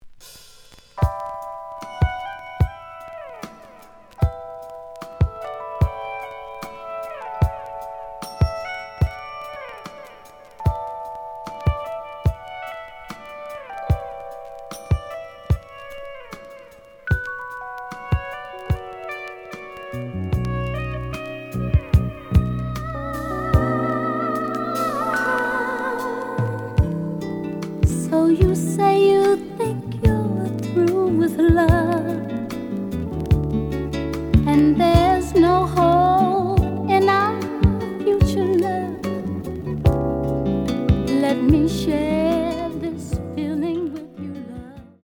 The audio sample is recorded from the actual item.
●Genre: Soul, 80's / 90's Soul
Looks good, but slight noise on both sides.